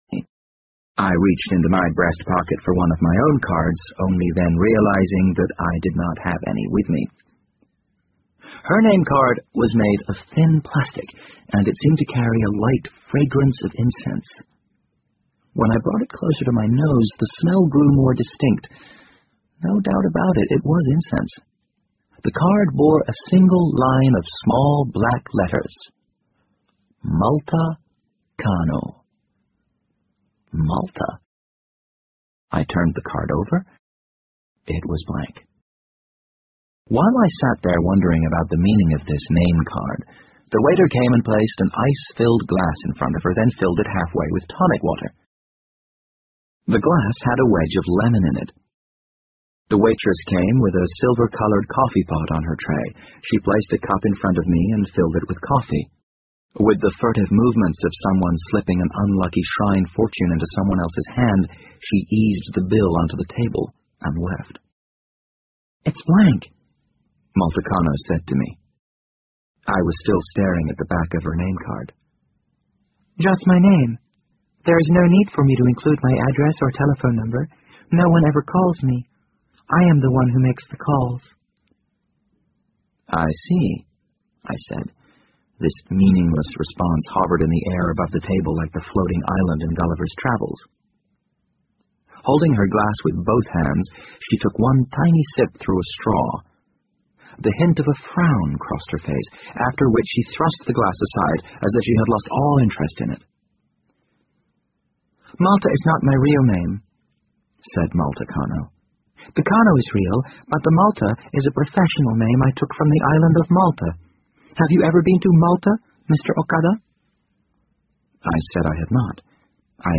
BBC英文广播剧在线听 The Wind Up Bird 18 听力文件下载—在线英语听力室